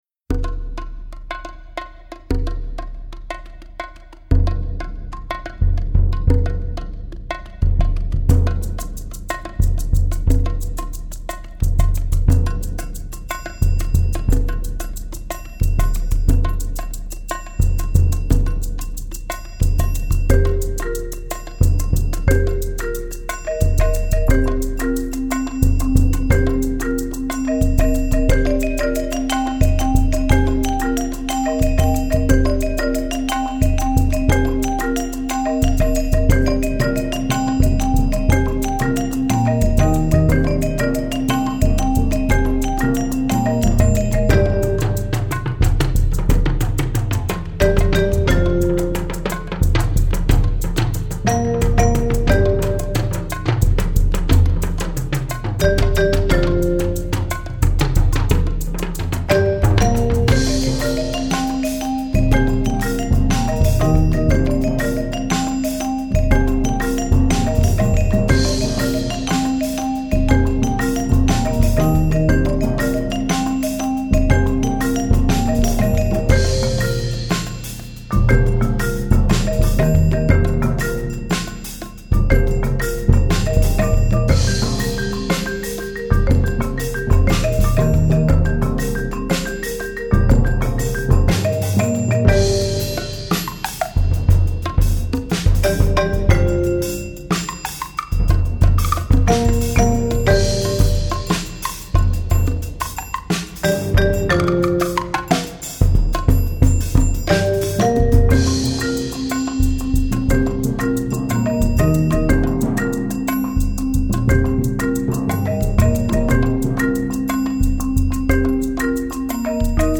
Voicing: Percussion Choir